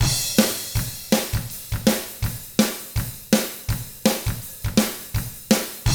164ROCK T1-L.wav